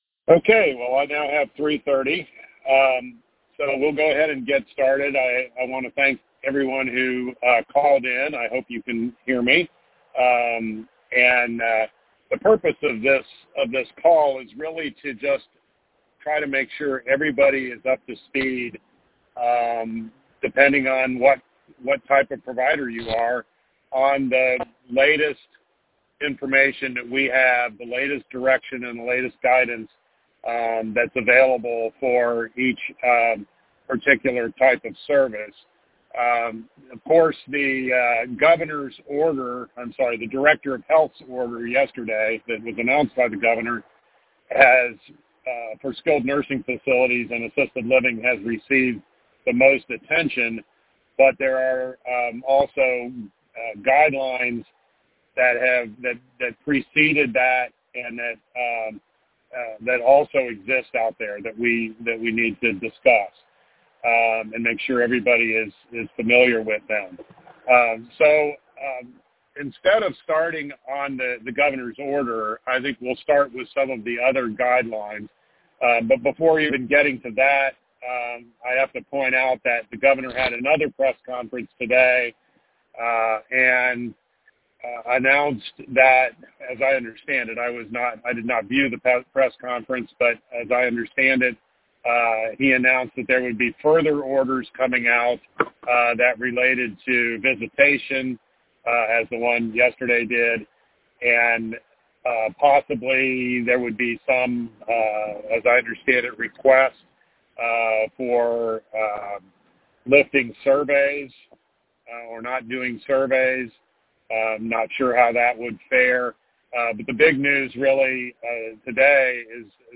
OHCA all-provider call . Yesterday afternoon, OHCA held a conference call open to all Ohio long-term services and supports providers to explain the current COVID-19 guidelines for assisted living, home care, hospice, ID/DD services, and skilled nursing.